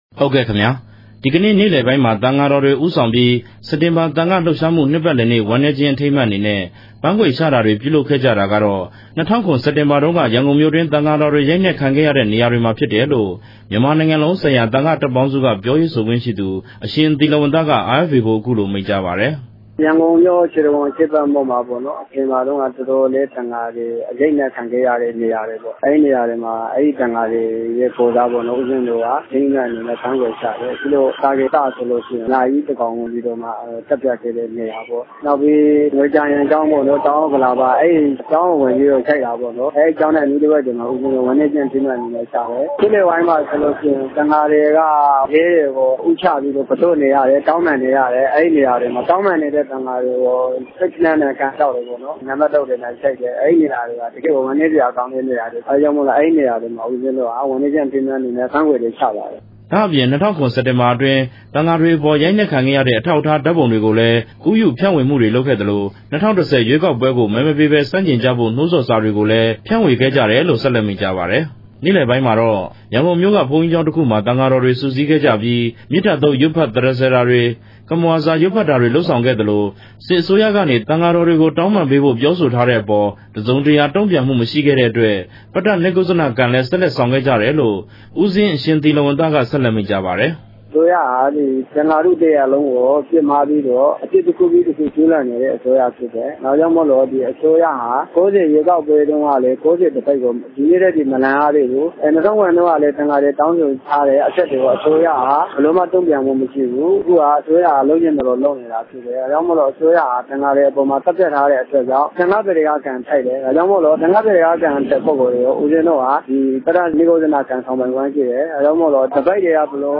သတင်းပေးပို့ချက်။